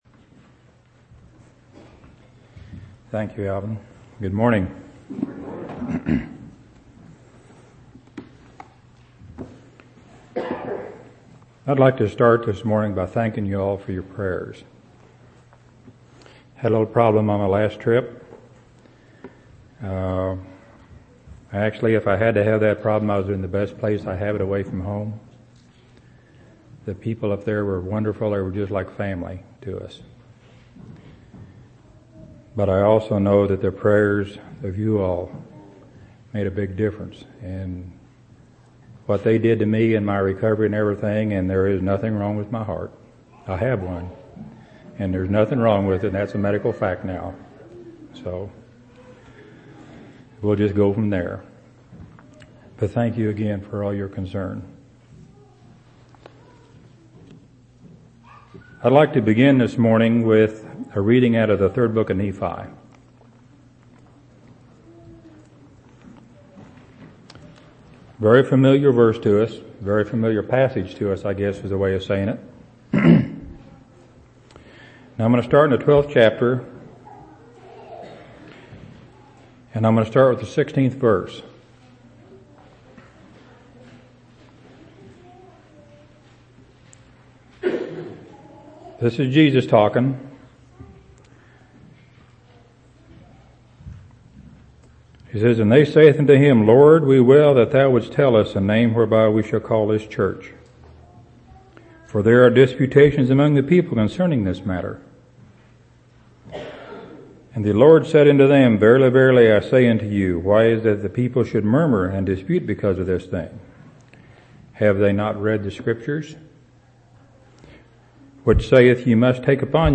3/21/2004 Location: Temple Lot Local Event